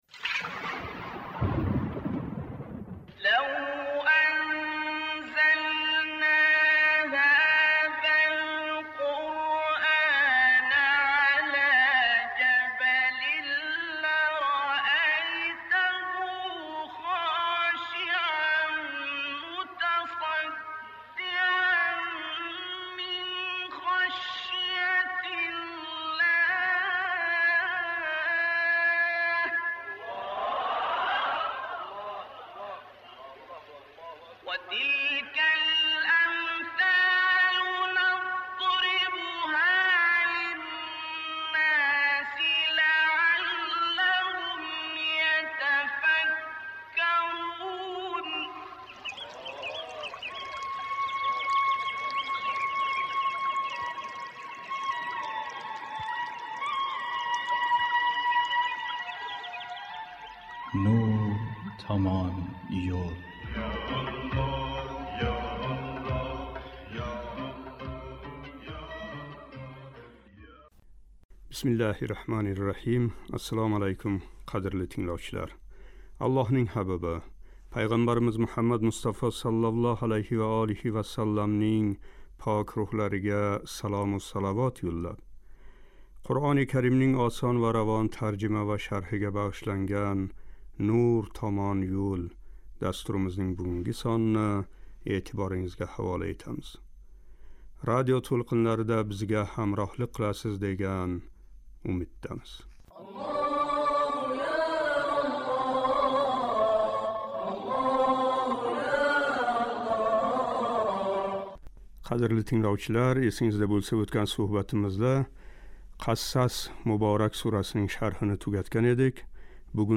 705-қисм."Анкабут" муборак сурасининг 1-7 - ояти карималари. Суҳбатимиз ибтидосида “Анкабут” муборак сураси 1-3-ояти карималарининг тиловатига қулоқ тутамиз.